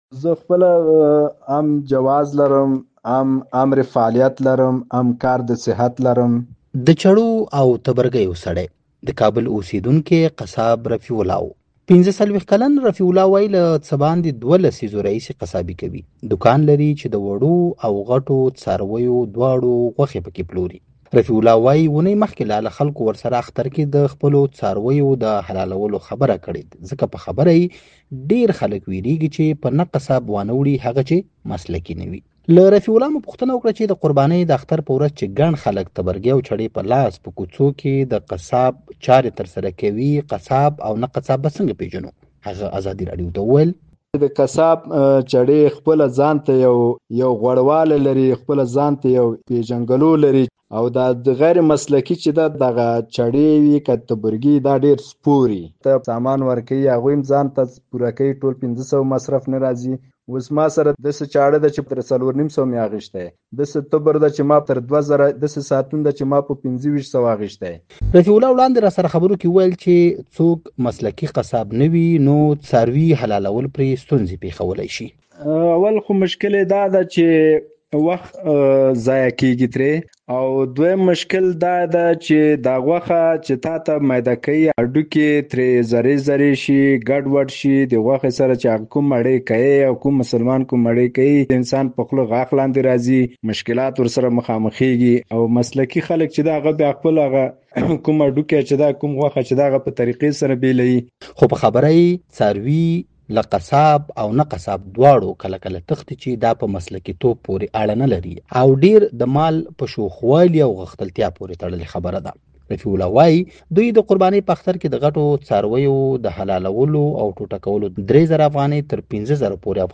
راډیويي مرکې